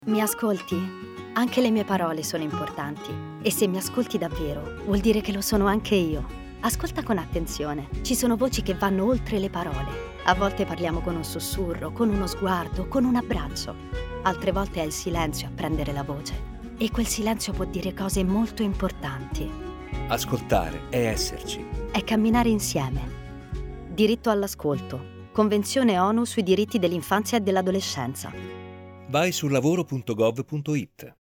Lo spot radio
Bambini, ragazzi e adolescenti sono i protagonisti di questa campagna, che ci ricorda come l’ascolto per le persone di minore età sia un vero e proprio diritto di poter esprimere la propria opinione su questioni che le riguardano, tenendo conto dell’età e del grado di maturità.